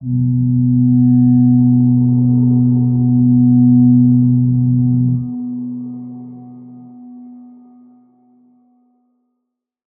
G_Crystal-B3-pp.wav